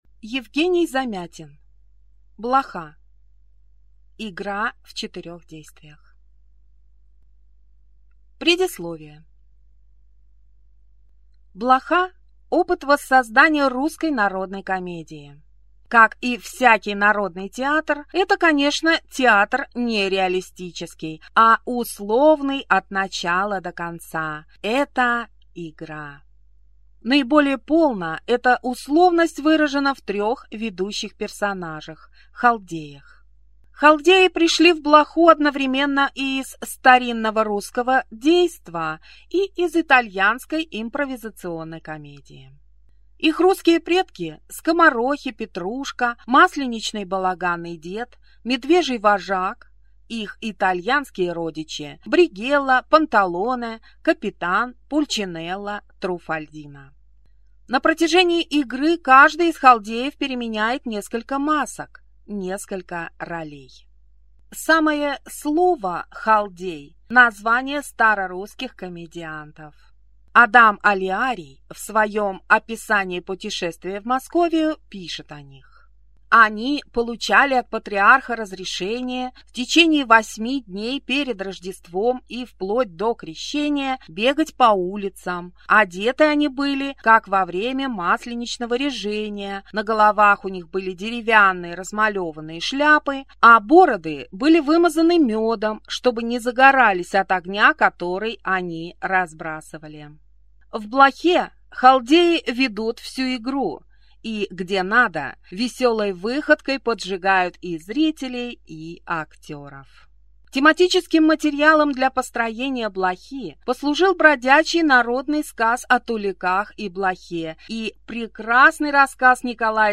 Аудиокнига Блоха | Библиотека аудиокниг
Прослушать и бесплатно скачать фрагмент аудиокниги